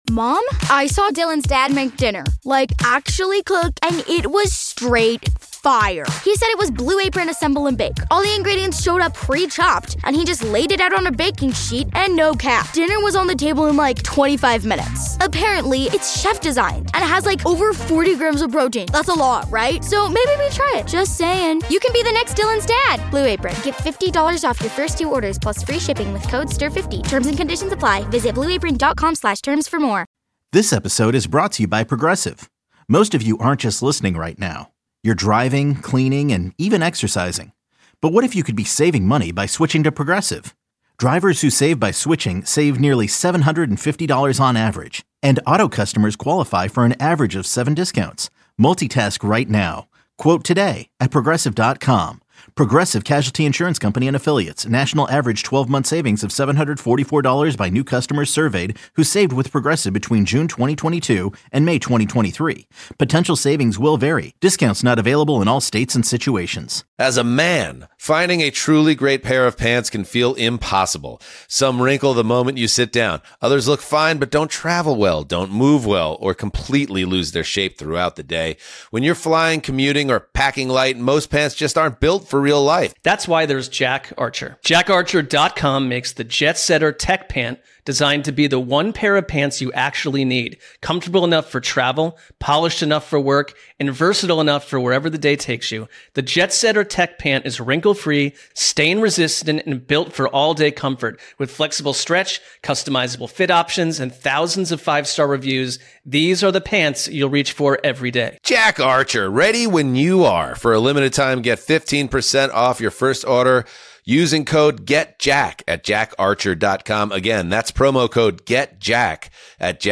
Best Interviews on WGR: Feb. 9-13